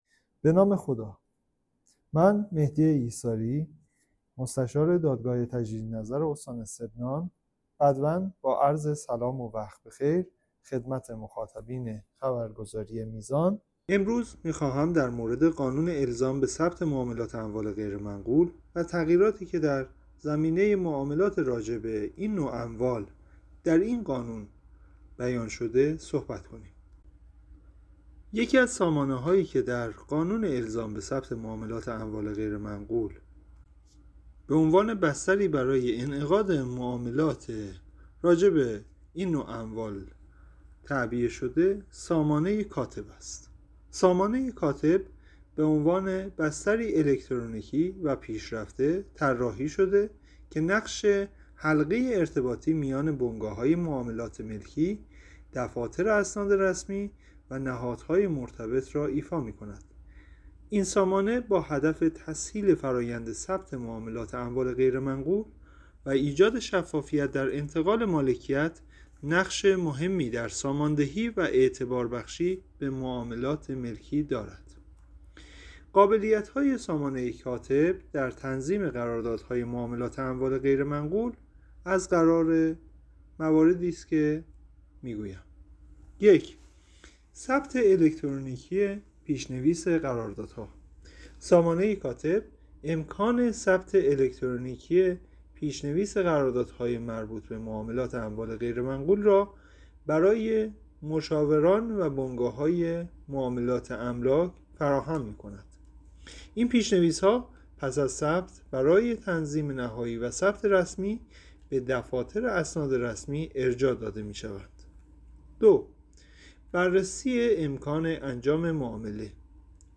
گفت‌و‌گو|